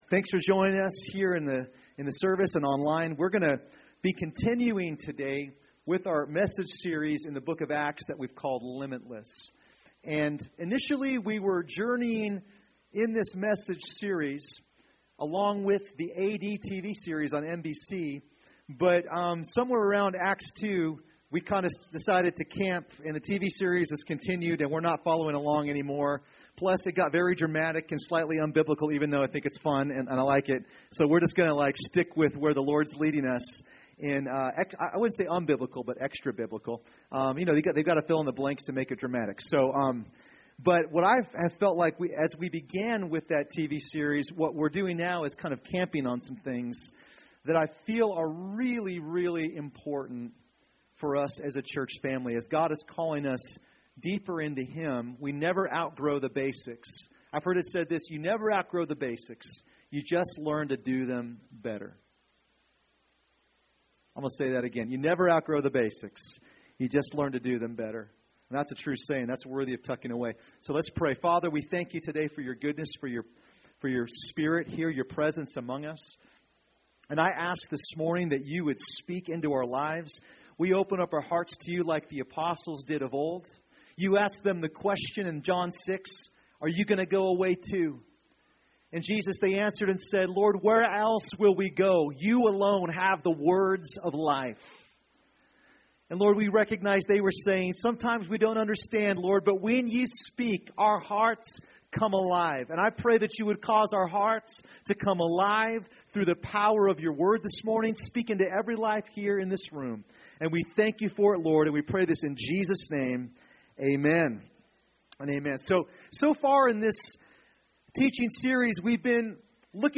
Recorded at New Life Christian Center, Sunday, May 17, 2015 at 11AM.
Preaching